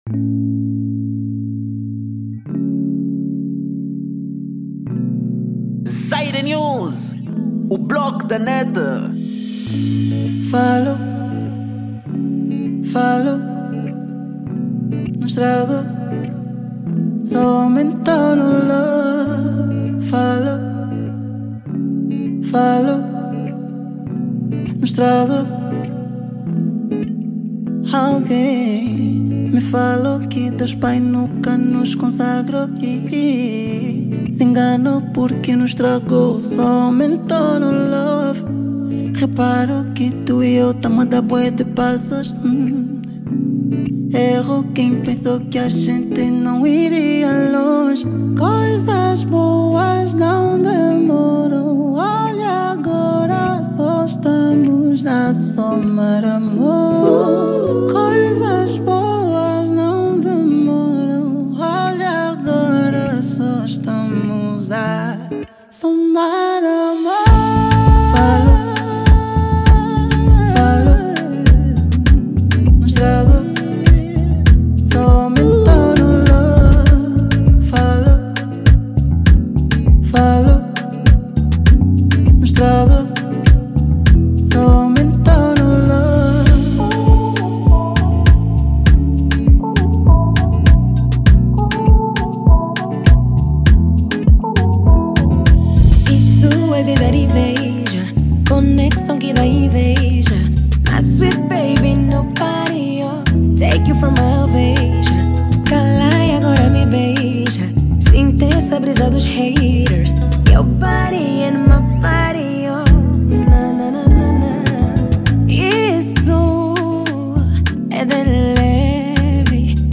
Género: Zouk